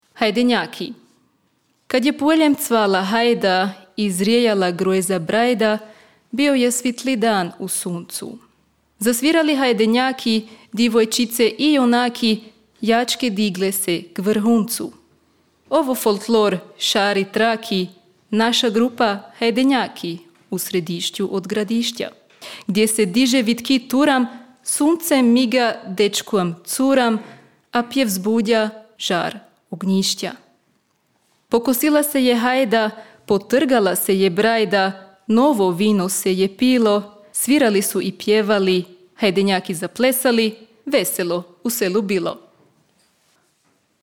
Leopold-maraton 1